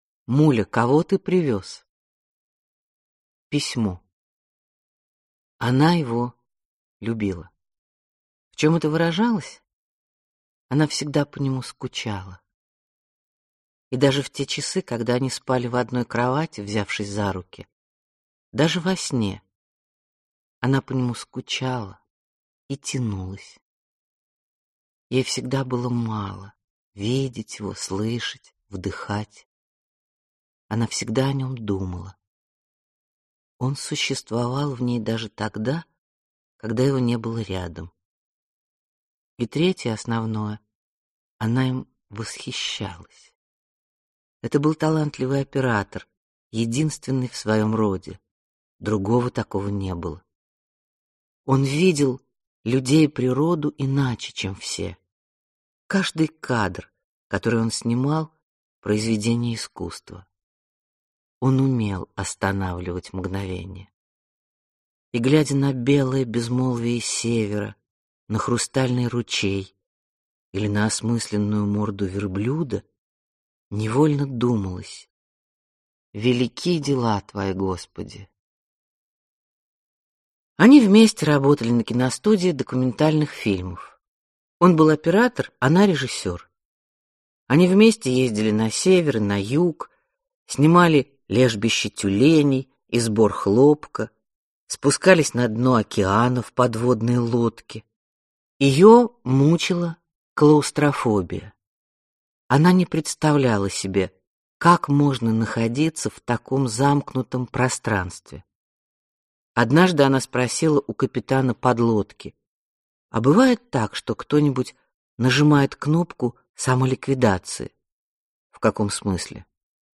Аудиокнига Муля, кого ты привез? (сборник) | Библиотека аудиокниг